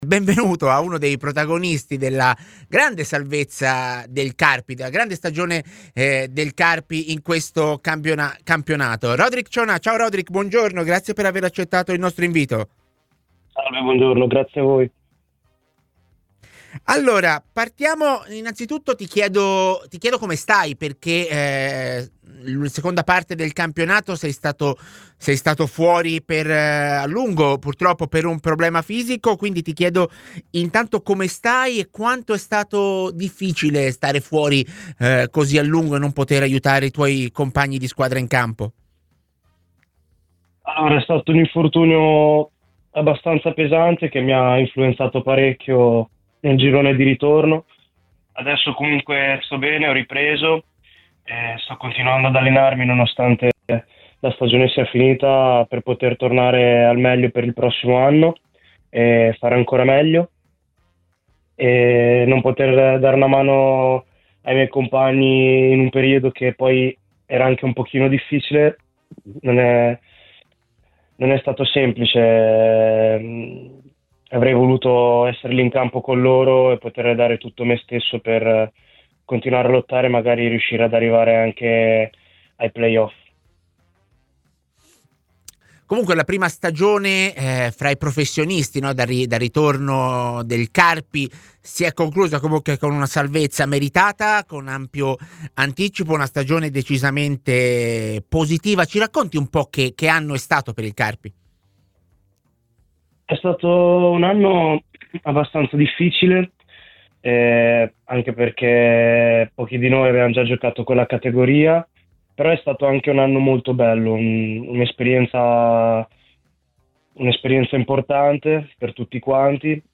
è intervenuto ai microfoni di TMW Radio durante la trasmissione A Tutta C.